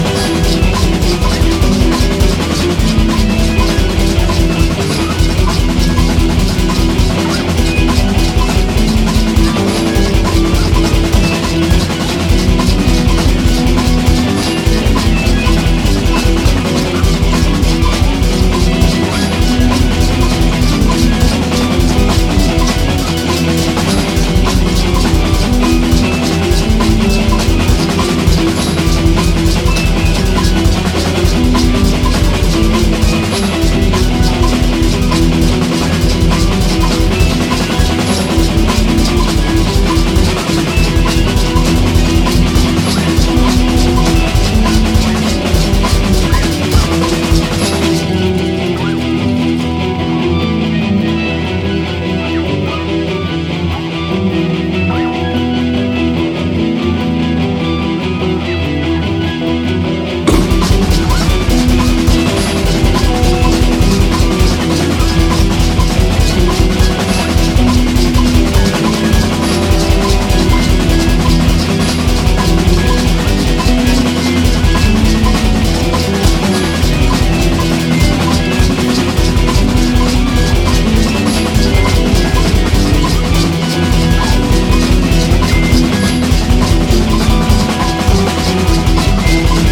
HEAVY METAL / HARD ROCK / GUITAR
永遠の名ギター・リフが轟く
ドラム・ブレイク入りのキャッチーなポップ・ロック
甲高いヴォーカルがまっちした疾走する
クラシカルなギターの多重録音によるオーケストレーションが美しいインスト・ナンバー